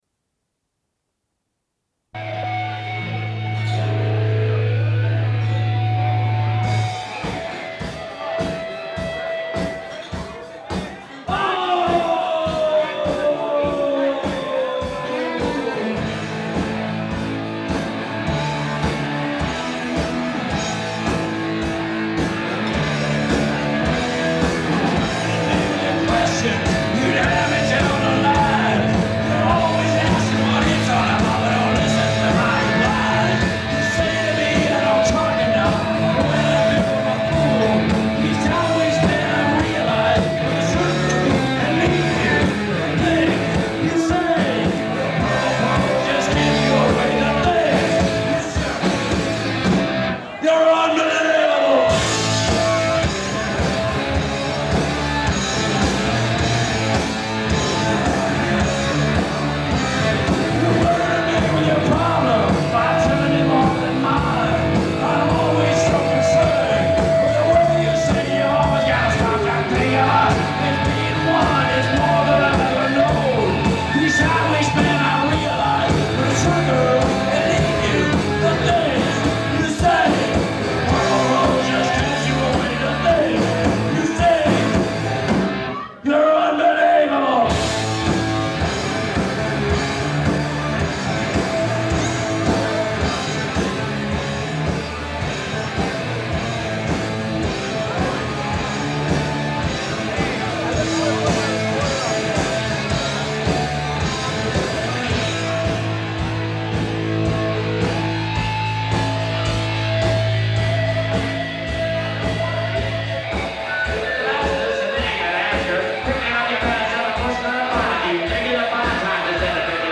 Athens June 94 (tape side A)